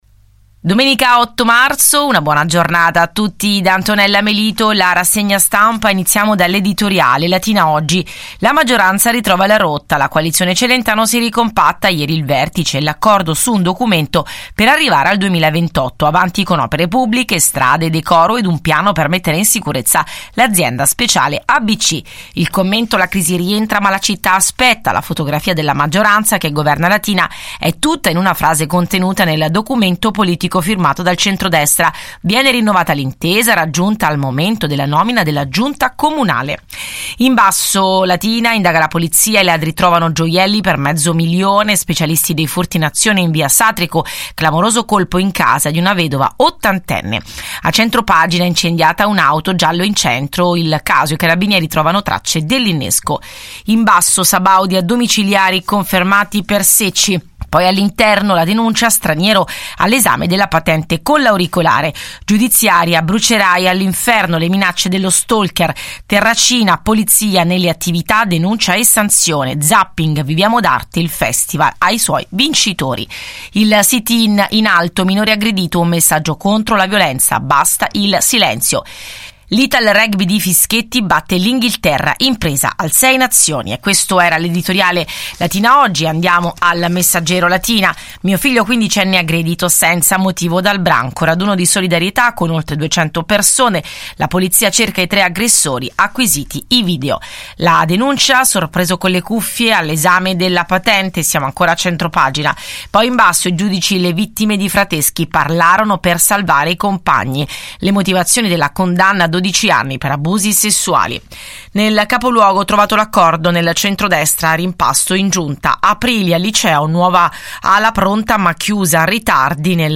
LATINA – Ogni giorno, dal lunedì al venerdì, alle 7 e il sabato alle 7, 30 è in onda su Radio Luna, Prima Pagina, uno sguardo ai titoli dei quotidiani locali in edicola.